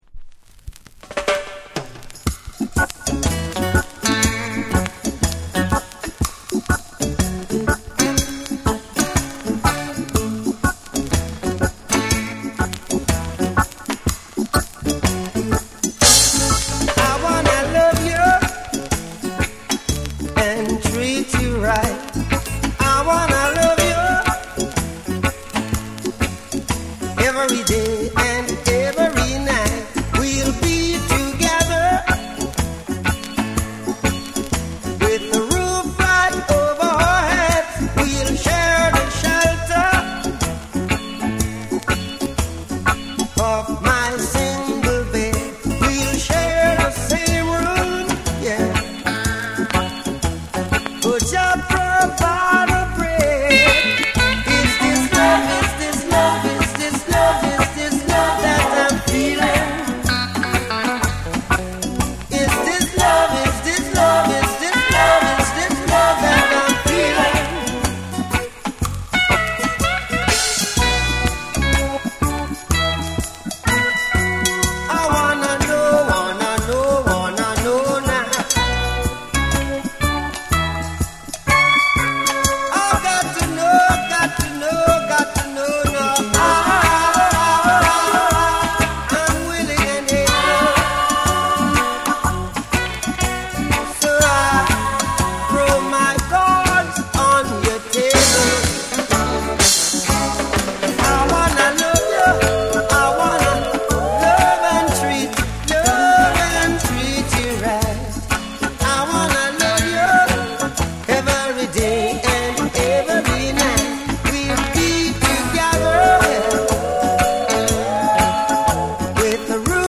盤面にスリキズ、ノイズ入る箇所あり。
REGGAE & DUB